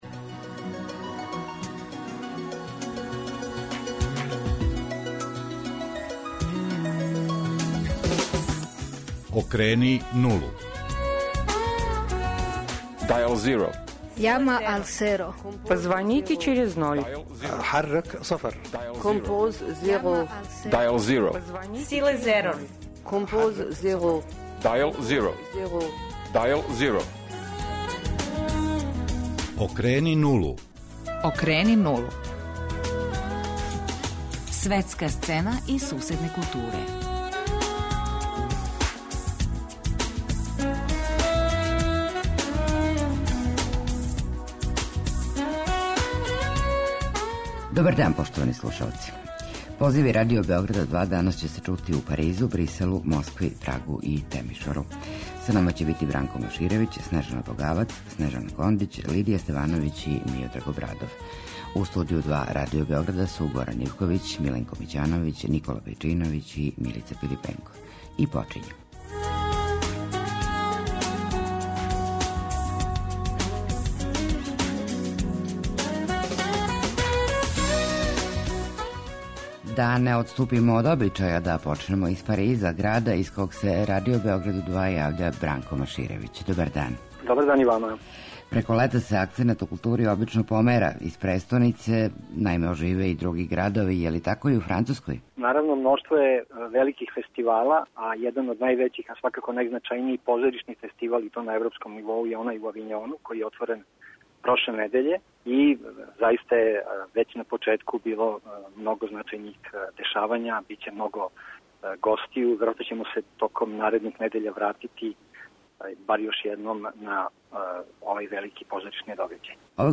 О свему томе, уз добродошле коментаре и појашњења, известиће дописници Радио Београда 2 из Париза, Брисела, Москве, Прага и Темишвара.